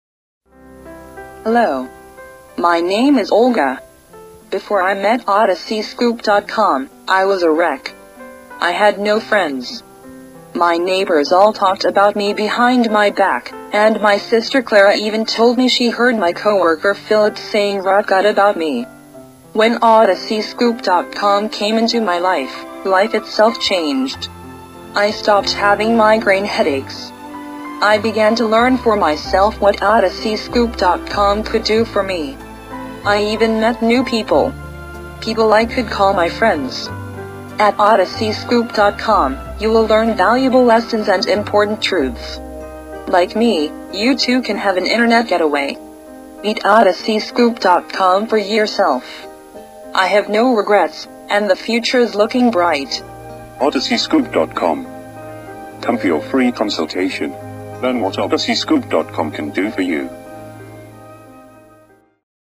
The voice in this audio commercial is not a real person! With advances in technology come better and better synthesized voices.